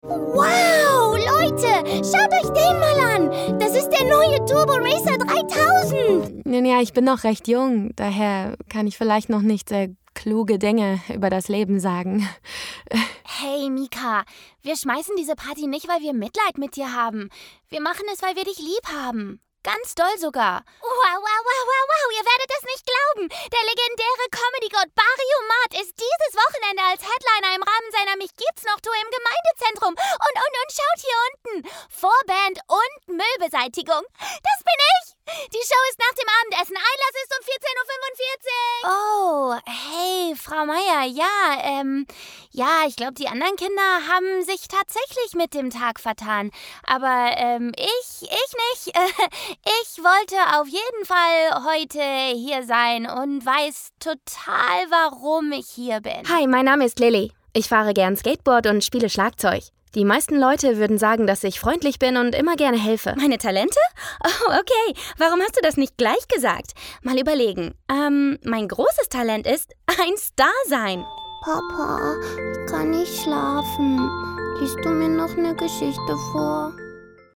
Animation
My voice is relatable, contemporary and youthful with a warm and textured sound.